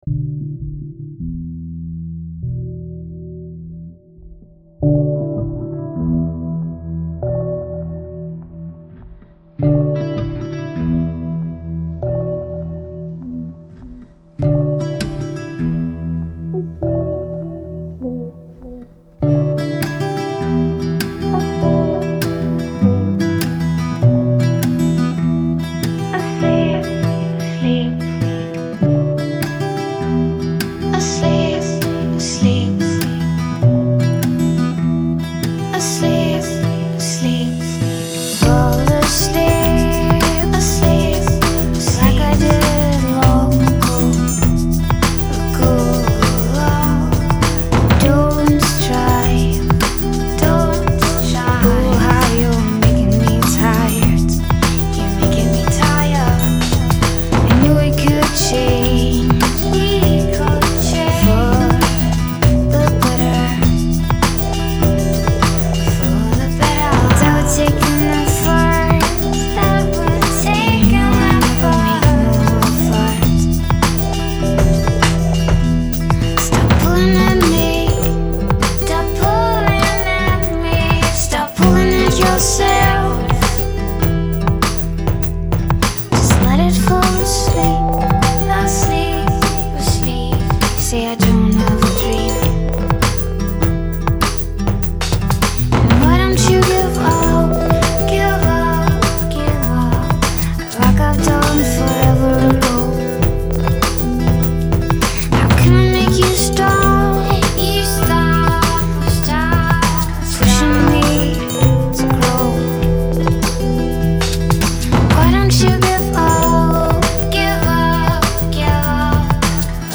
Things begin in a gentle swing
Portishead-ish trio
drummer